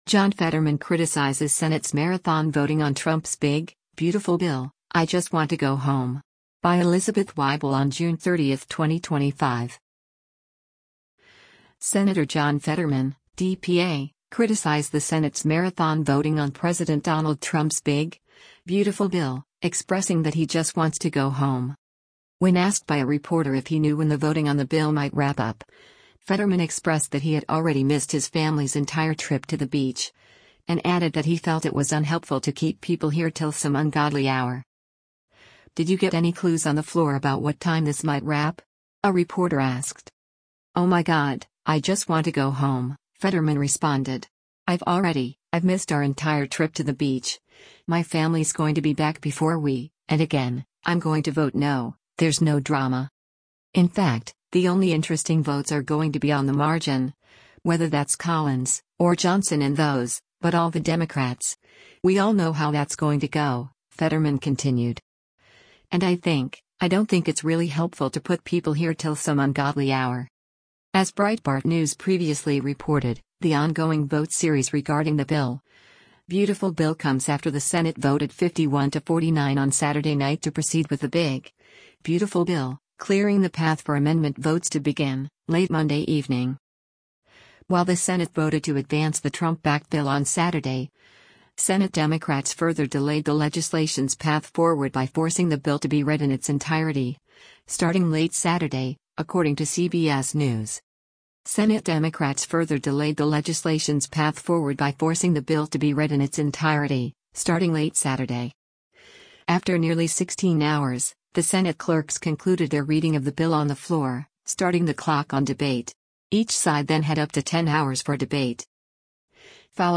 “Did you get any clues on the floor about what time this might wrap?” a reporter asked.